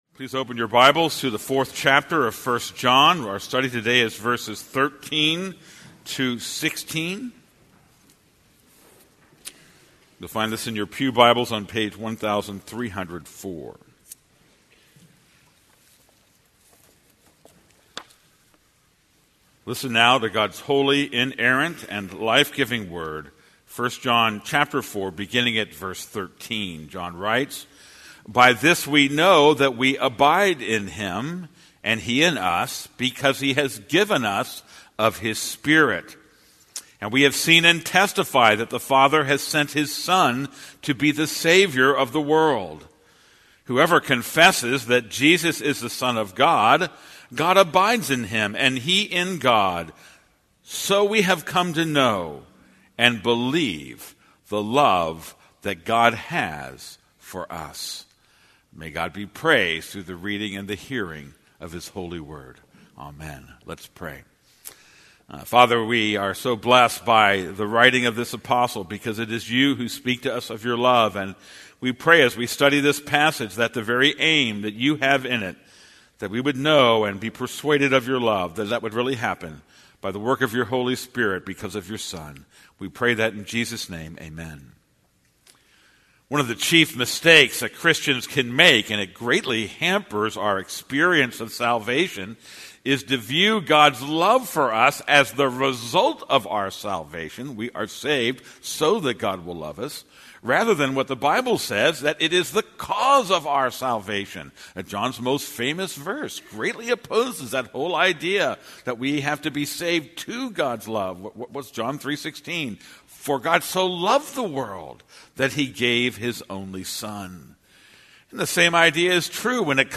This is a sermon on 1 John 4:13-16.